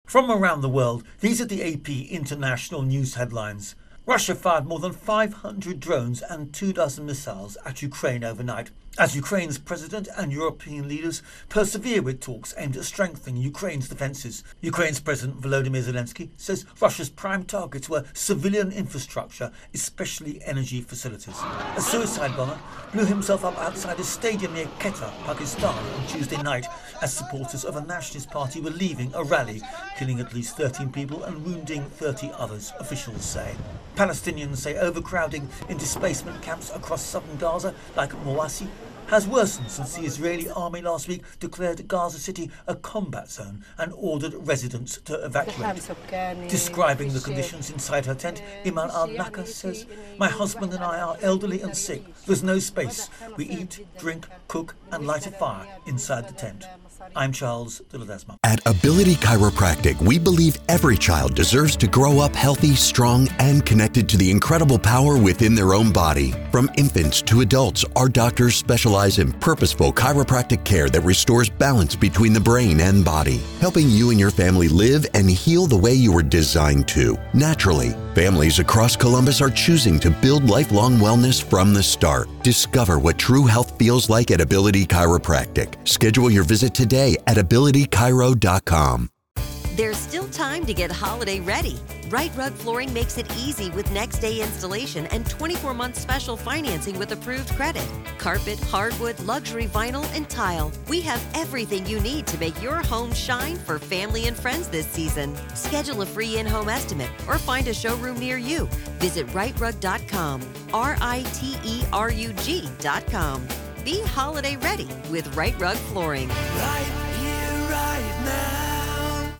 The latest international news headlines